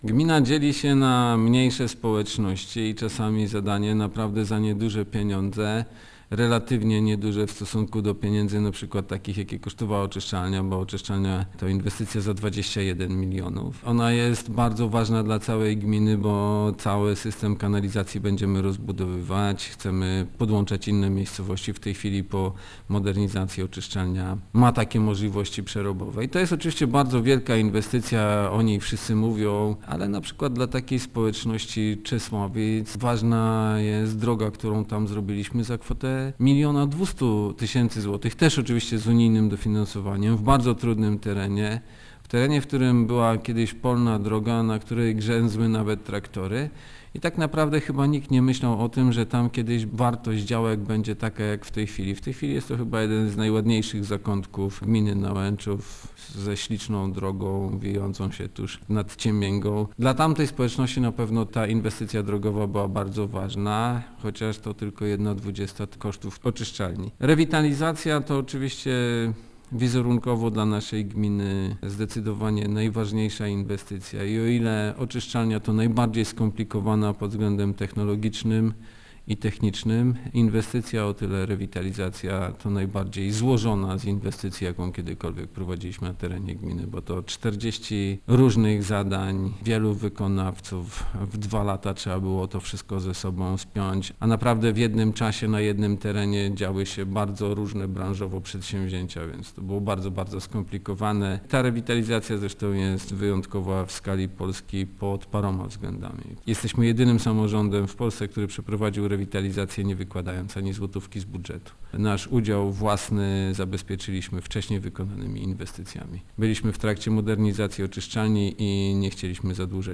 Wśród najważniejszych inwestycji burmistrz Ćwiek wymienia nie tylko wartą ponad 21 milionów złotych rozbudowę oczyszczalni ścieków czy rewitalizację centrum miasta za kilkanaście milionów, ale także mniej kosztowne, choć równie ważne dla mieszkańców gminy, zadania: